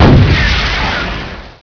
rocket_fire.wav